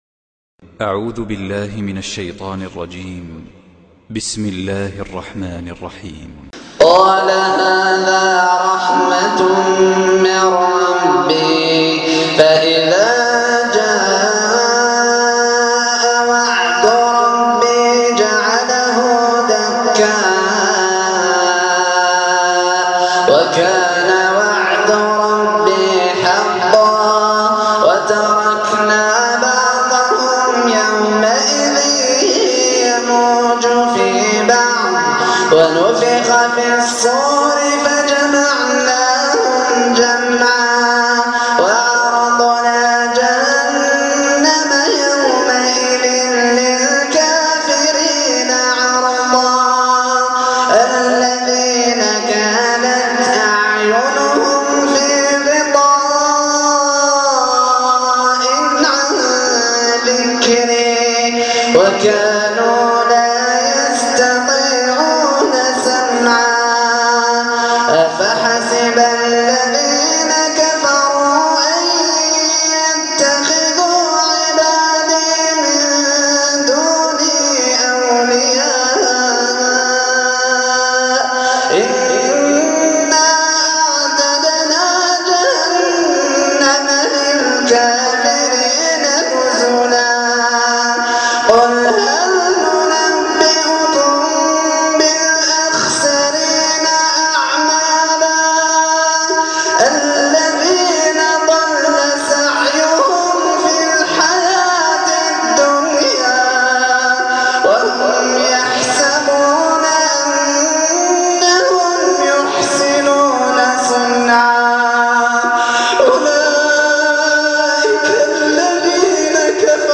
تلاوة خاشعة مبكية من صلاة التهجد